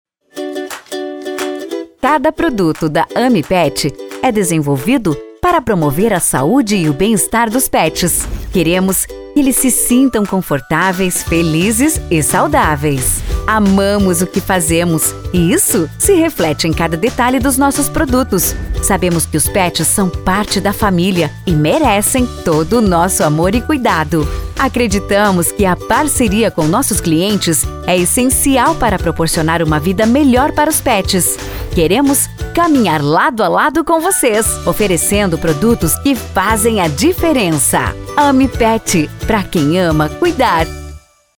Estilo Animado: